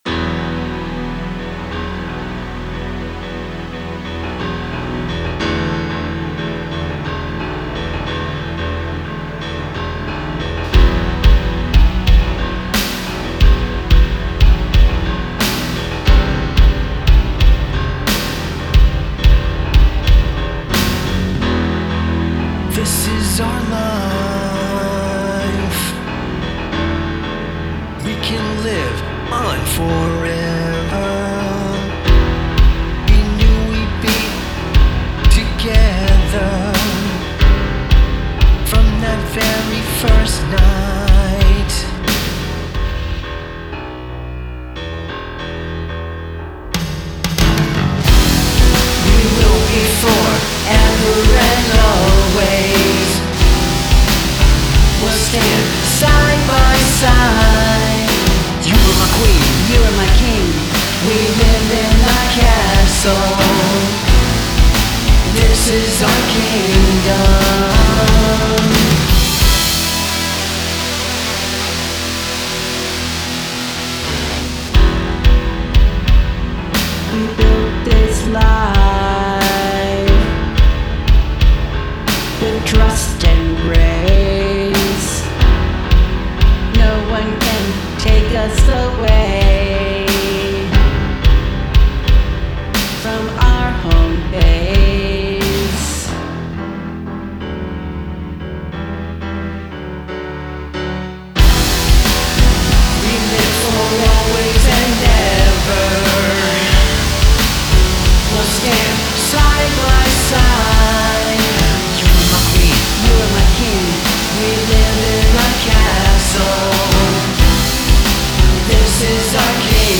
Genre: POP
A power ballad